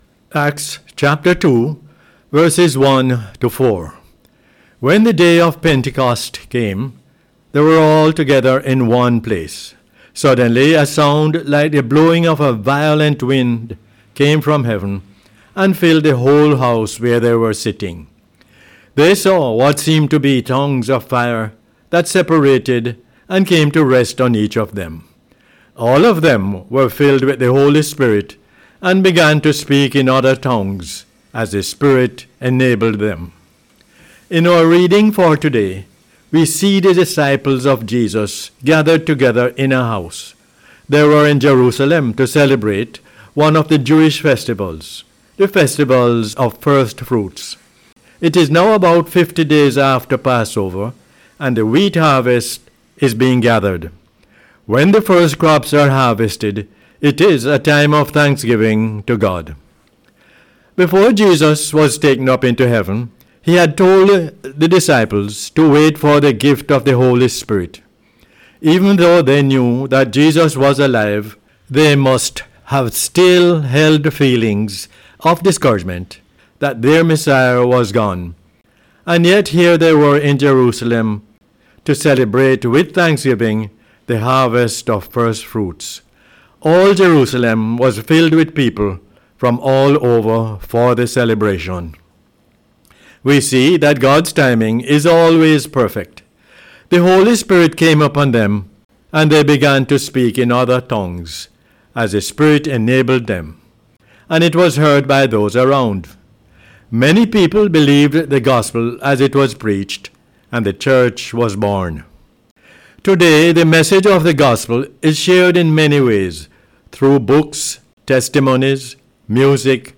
This “Word For Jamaica” was aired on the radio on 16 May 2025.